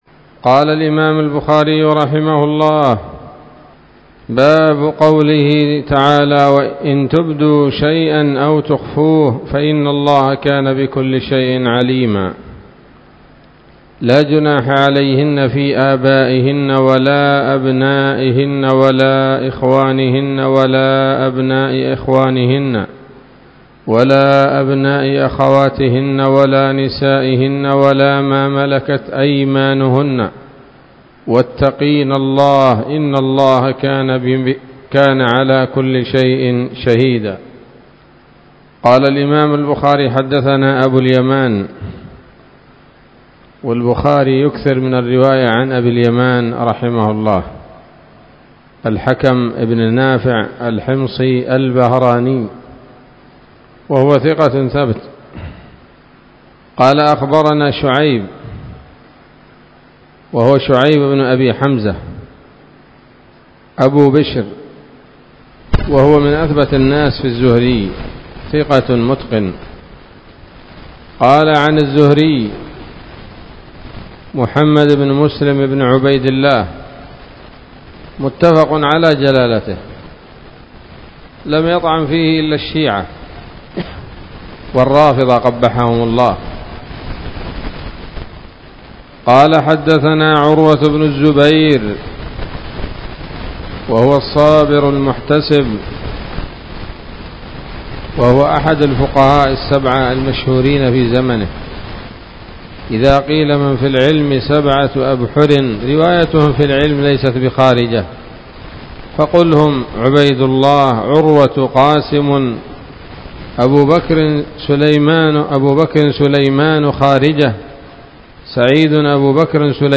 الدرس السابع بعد المائتين من كتاب التفسير من صحيح الإمام البخاري